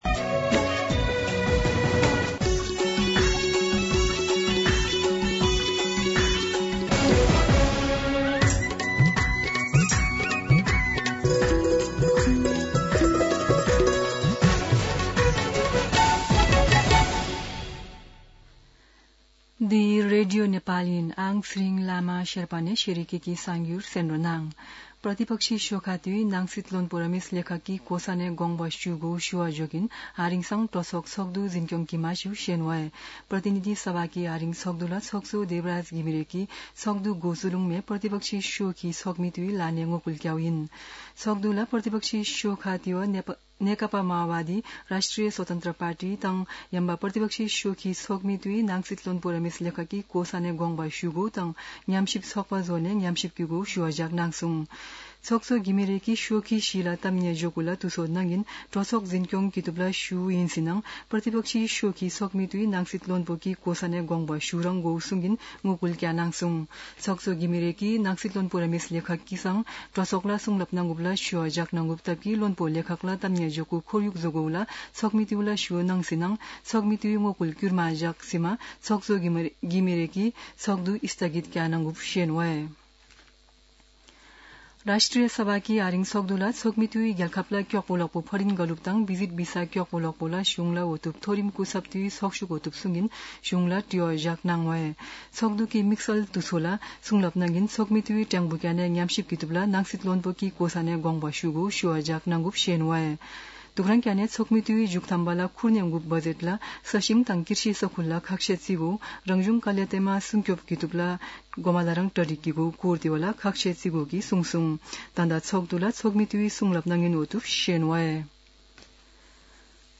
शेर्पा भाषाको समाचार : १४ जेठ , २०८२
Sherpa-News-02-14.mp3